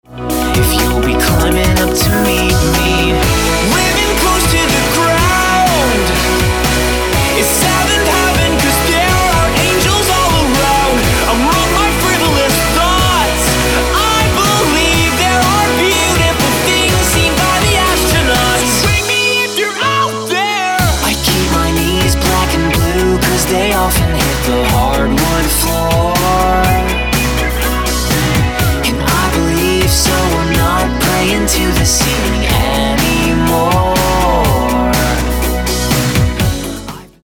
americká elektro-popová skupina